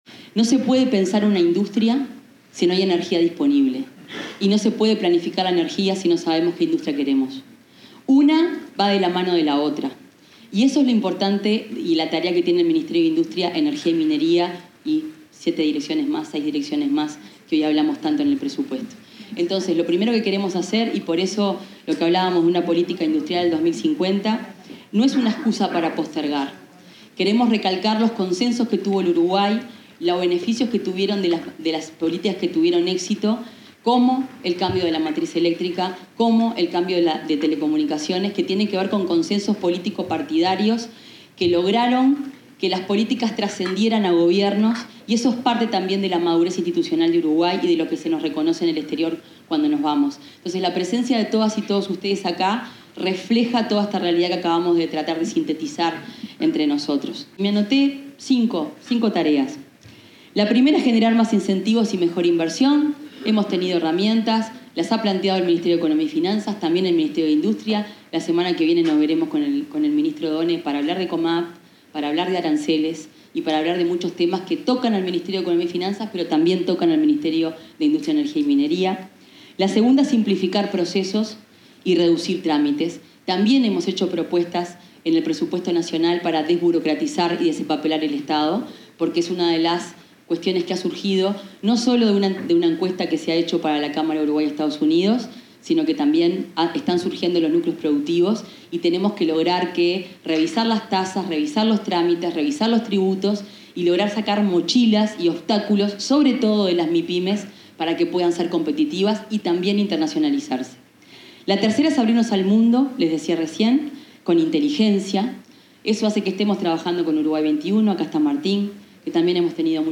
Palabras de la ministra Fernanda Cardona
Palabras de la ministra Fernanda Cardona 13/11/2025 Compartir Facebook X Copiar enlace WhatsApp LinkedIn En ocasión del 127.° aniversario de la Cámara de Industrias del Uruguay, la ministra de Industria, Energía y Minería, Fernanda Cardona, se refirió a los principales desafíos del sector y la construcción de una política pública industrial.
Oratoria.mp3